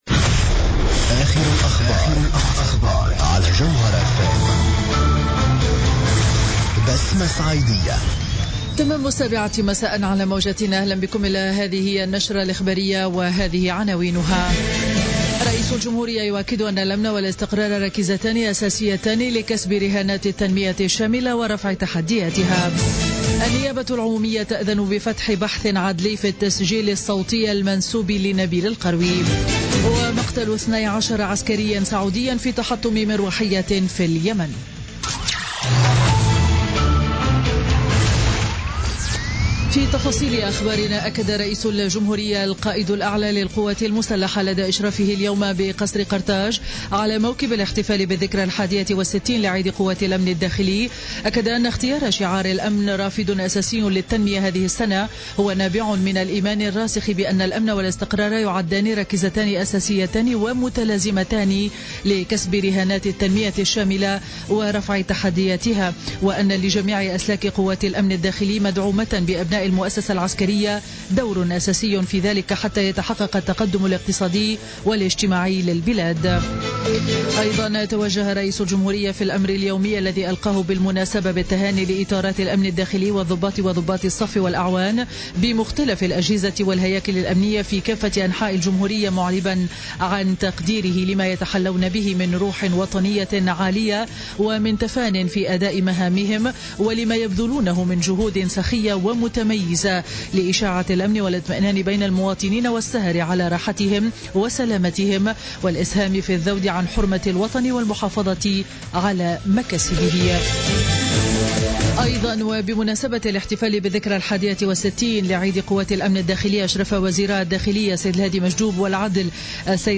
نشرة أخبار السابعة مساء ليوم الثلاثاء 18 أفريل 2017